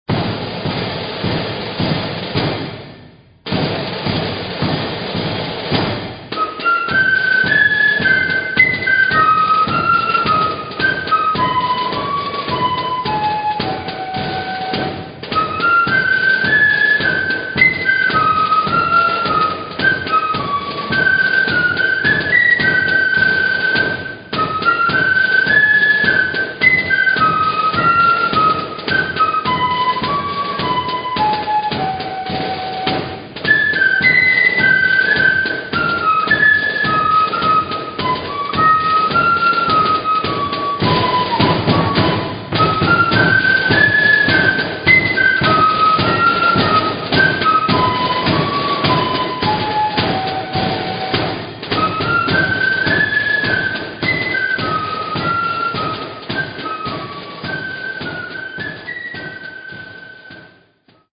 melody flute bands